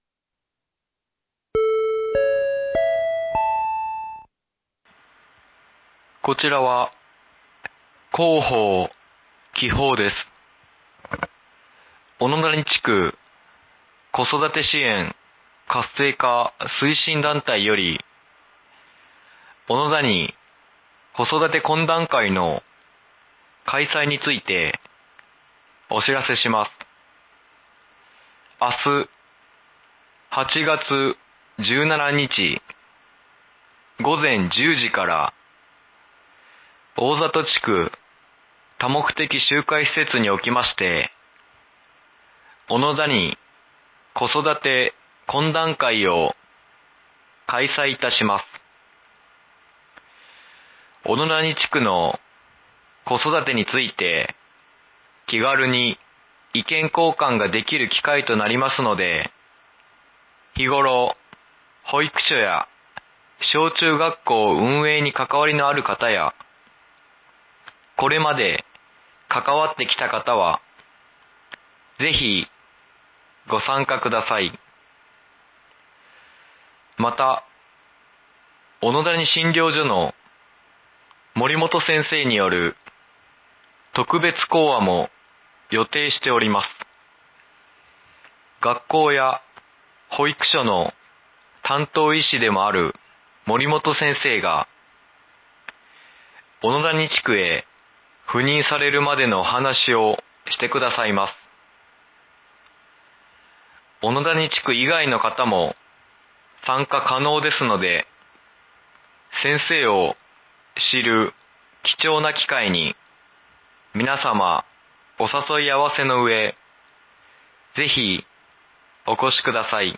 防災無線放送内容 | 紀宝町防災メール配信サービス